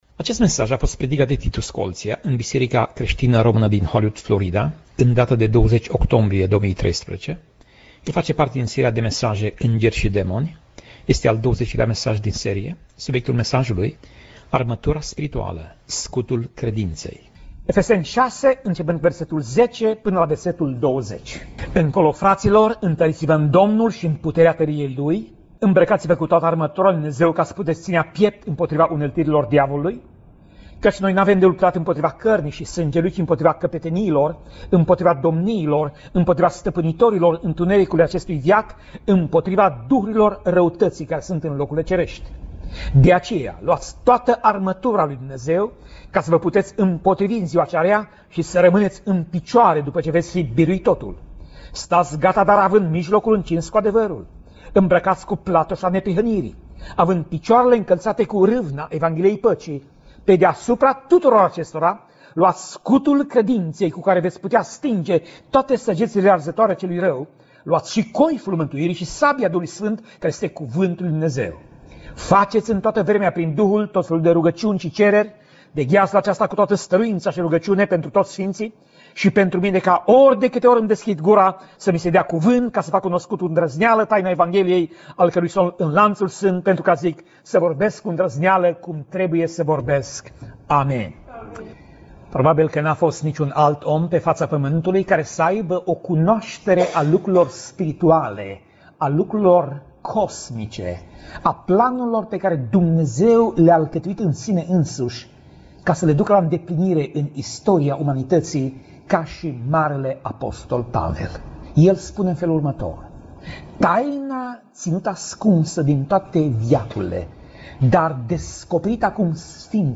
Pasaj Biblie: Efeseni 6:10 - Efeseni 6:20 Tip Mesaj: Predica